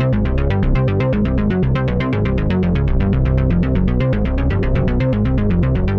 Index of /musicradar/dystopian-drone-samples/Droney Arps/120bpm
DD_DroneyArp2_120-C.wav